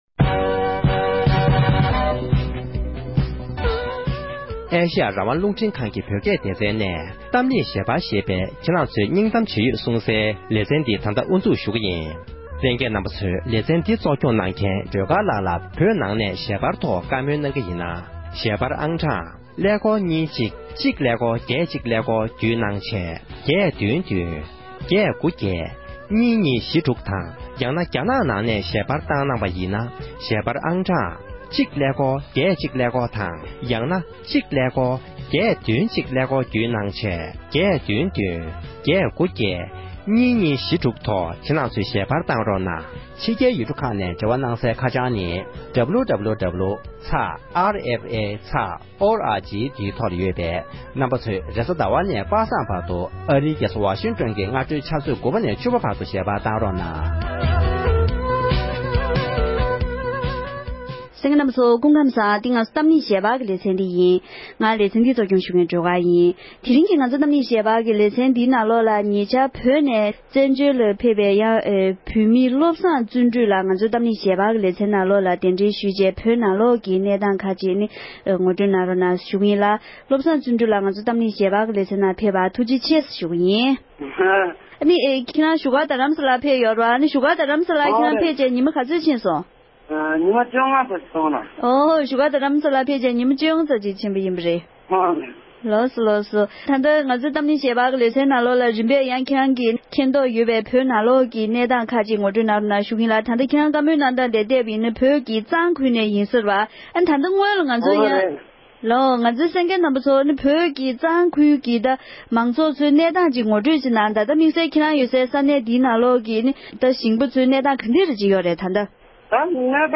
བོད་ནས་ཕེབས་མཁན་ཞིག་ནས་བོད་ཀྱི་ས་གནས་ཁག་གི་ནང་དུ་དམ་བསྒྲགས་ཆེ་རུ་ཕྱིན་ཡོད་སྐོར་གྱི་ངོ་སྤྲོད་གནང་བ།